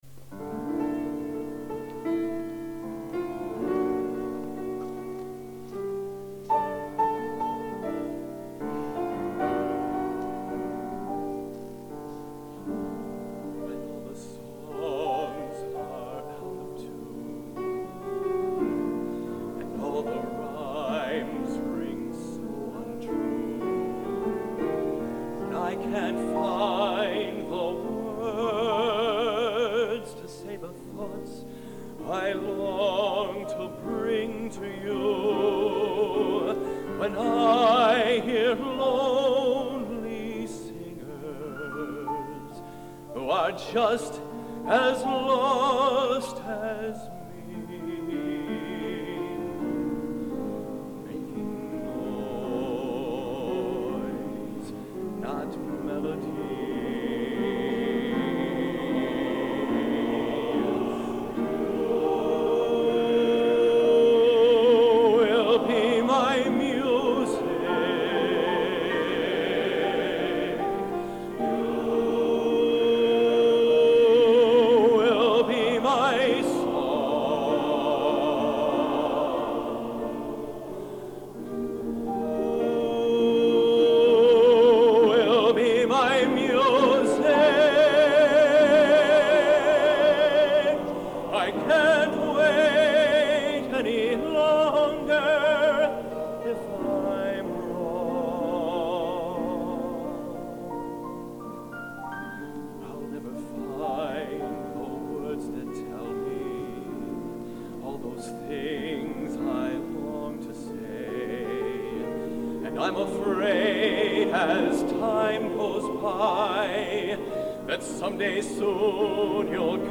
Collection: Stockholm, 1987
Location: Old Academy of Music, Stockholm, Sweden